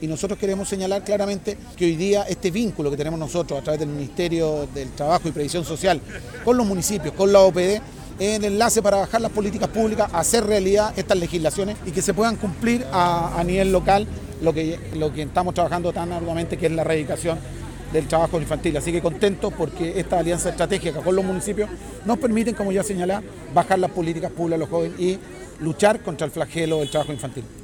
SEREMI-DEL-TRABAJO-Y-PREVISION-SOCIAL-Rodrigo-Kopaitic.mp3